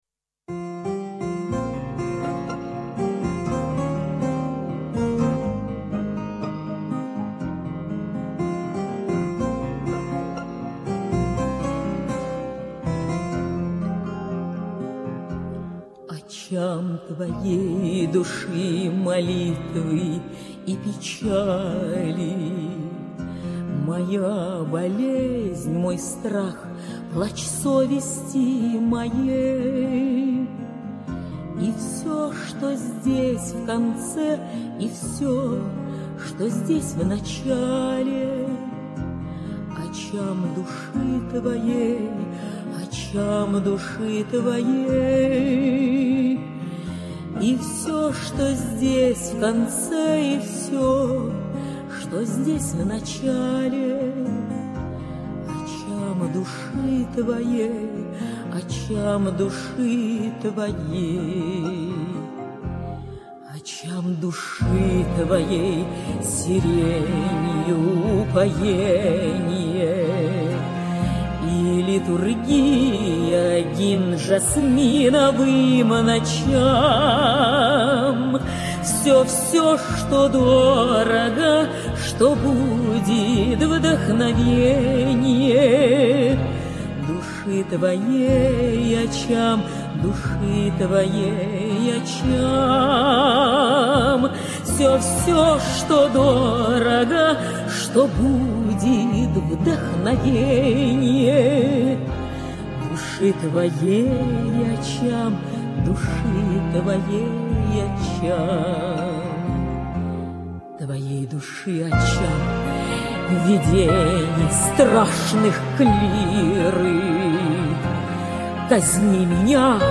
Не буду убирать красивый вальс.